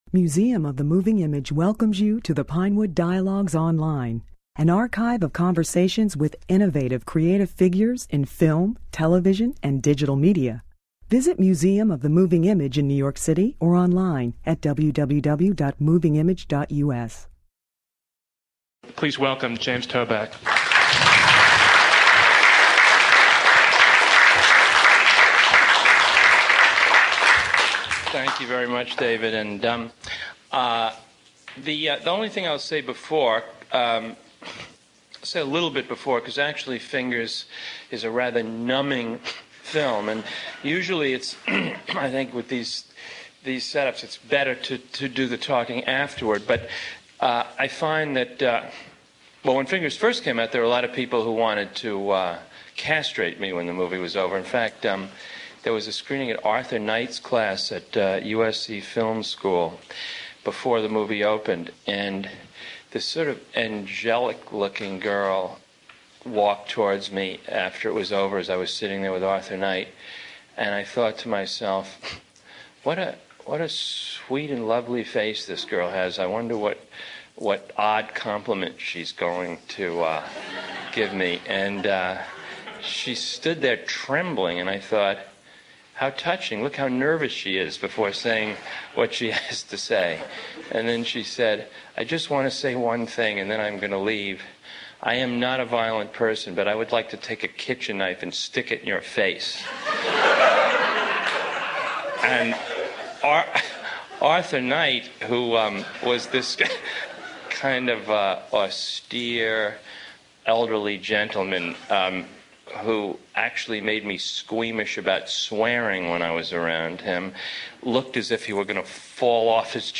Ever since his directorial debut Fingers, a film that, like its concert-pianist/hit-man hero, is torn between high culture and low life, James Toback has divided audiences. His champions admire his unique mixture of pulp and art, while some, like an audience member heard in this discussion, are appalled by his approach to violence and sexuality.